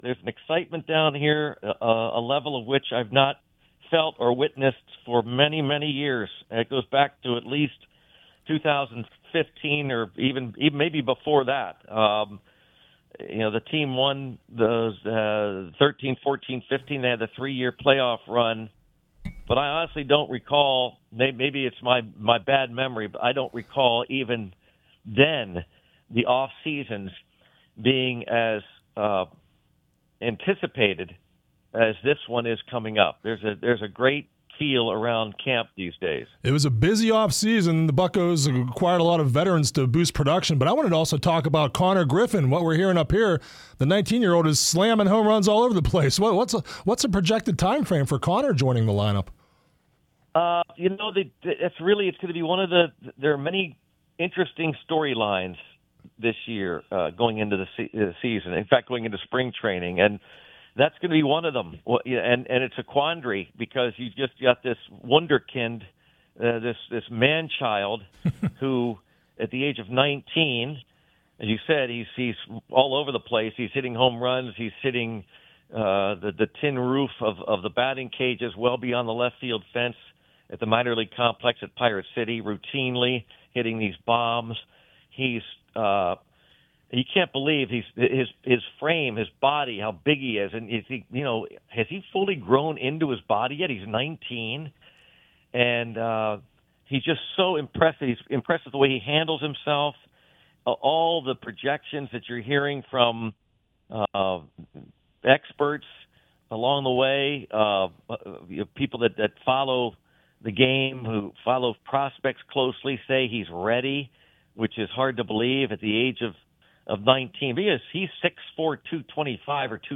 Pirates announcer gives spring training report on Beaver County Radio - Beaver County Radio